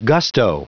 Prononciation du mot : gusto
gusto.wav